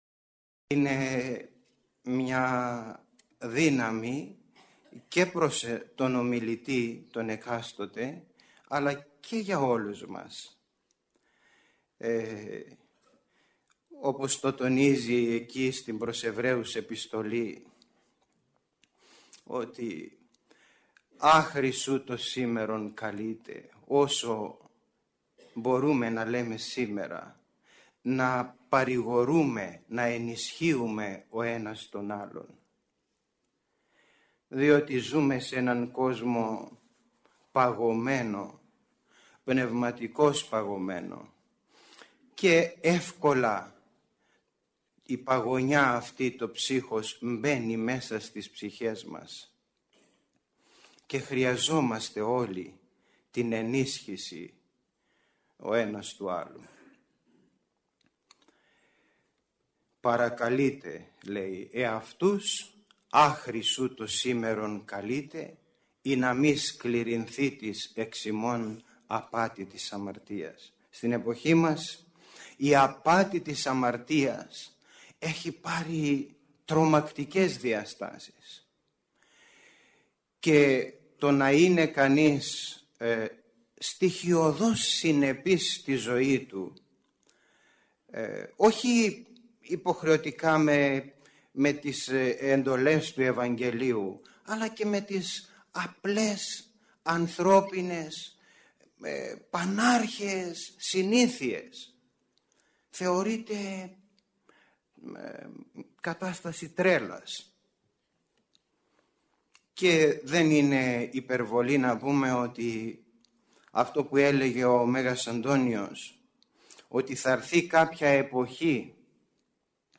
Πρόκειται για ομιλία με πολλές σύγχρονες κοινωνιολογικές και εσχατολογικές προεκτάσεις. «Πραγματοποιήθηκε” στην αίθουσα της Χριστιανικής ενώσεως Αγρινίου.